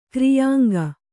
♪ kriyāŋga